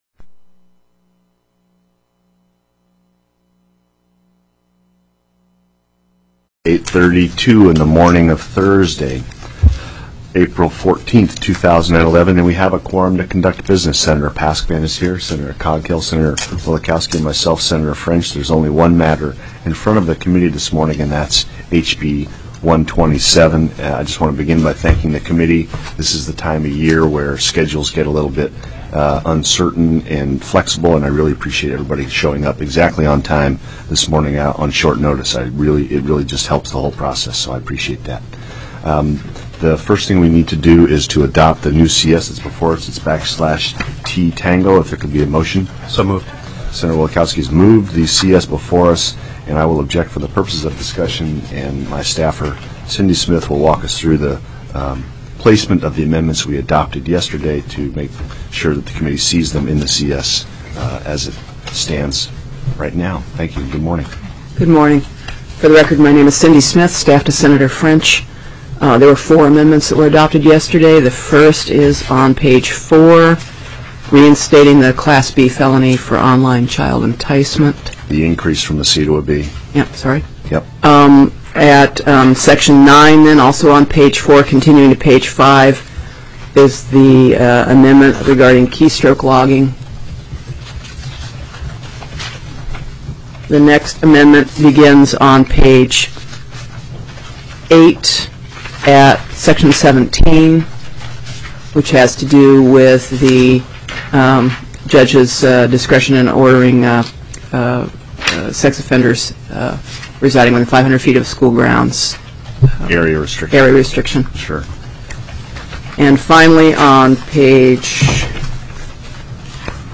04/14/2011 08:30 AM Senate JUDICIARY
+= HB 127 OMNIBUS CRIME BILL TELECONFERENCED